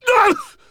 B_pain2.ogg